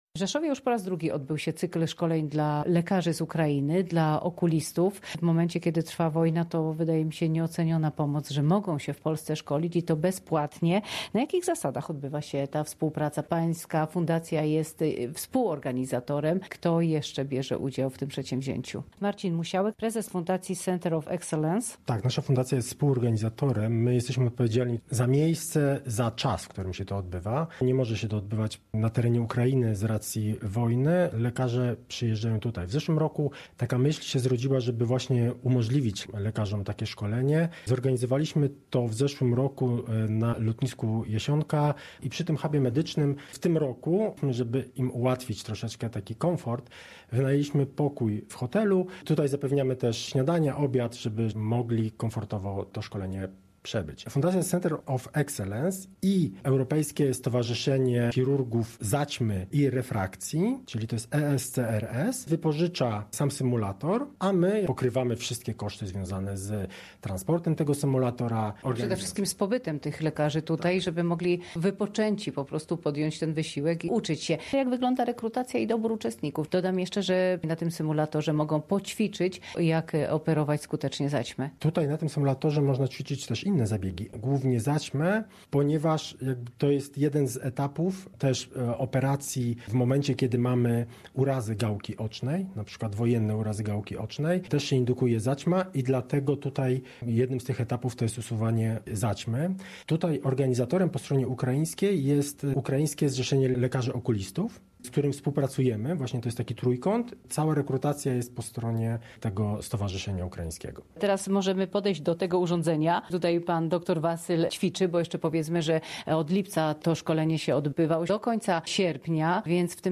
Z uczestnikiem szkolenia i współorganizatorem rozmawiała